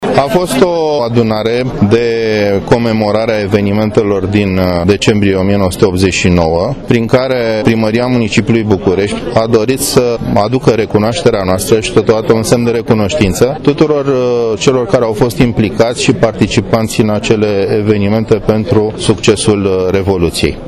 diplomaPrimăria Capitalei a acordat diplome revoluționarilor din 1989, în cadrul unei festivități.
Dan Darabonţ a explicat, de asemenea, semnificaţia acestui eveniment: